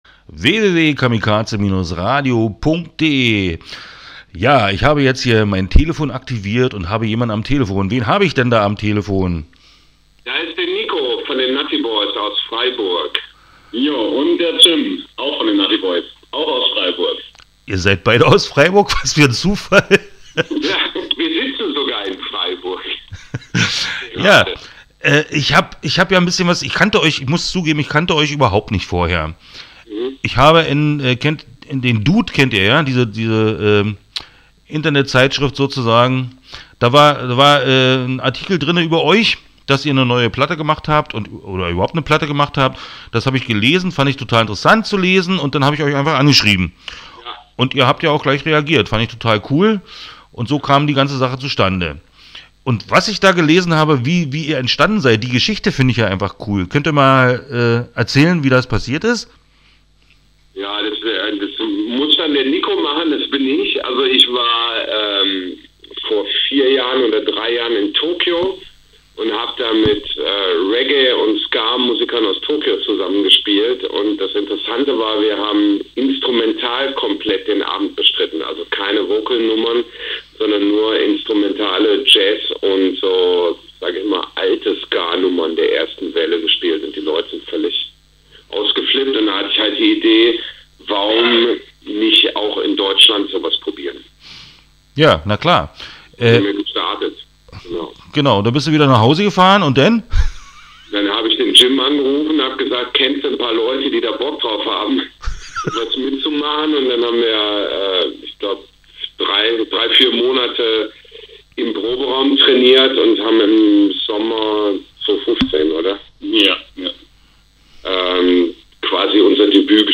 Interview Teil 01 (10:38)